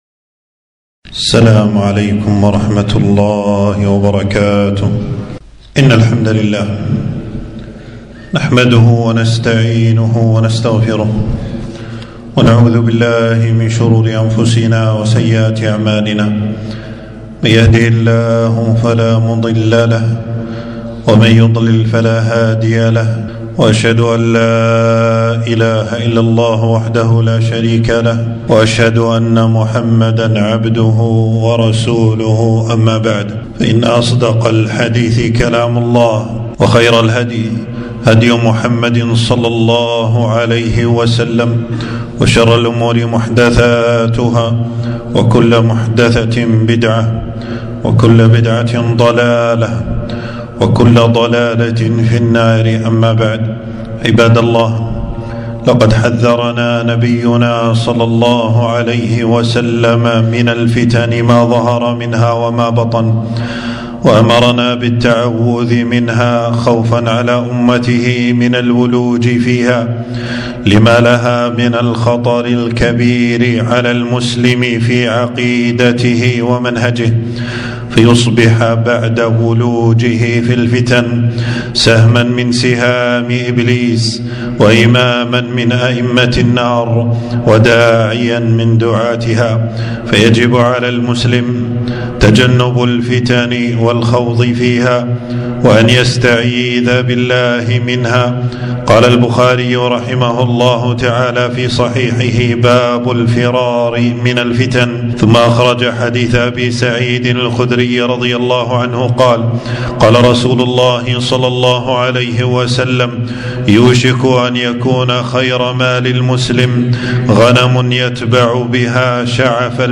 خطبة - التحذير من دعاة الفتن والتكفير - دروس الكويت